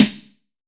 B.B SN 6.wav